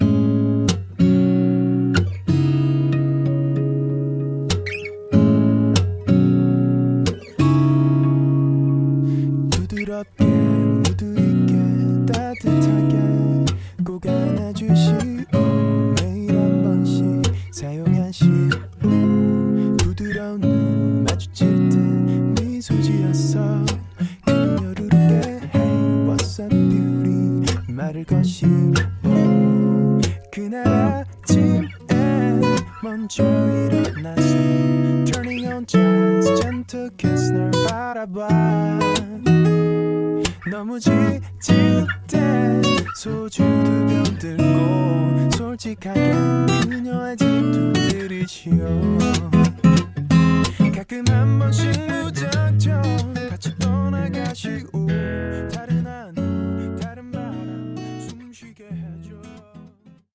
음정 -1키 3:02
장르 가요 구분 Voice Cut